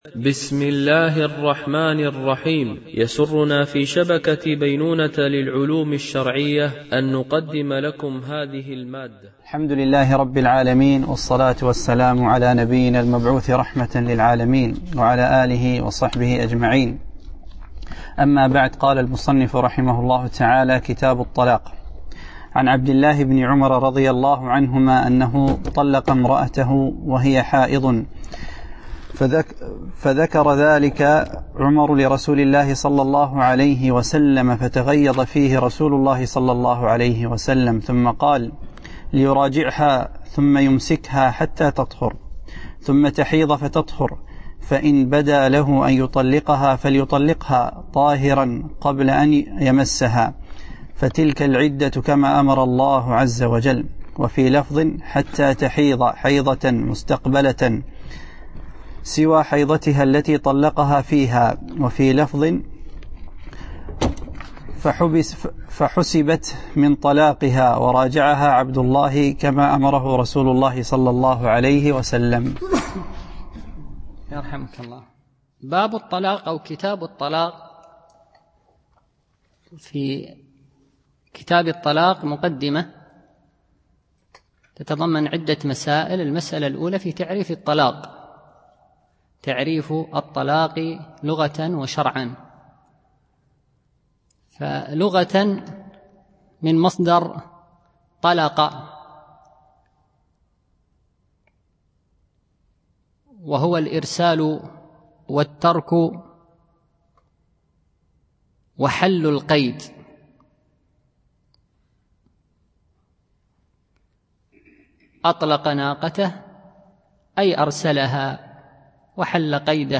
شرح عمدة الأحكام - الدرس 75 ( الحديث 319 )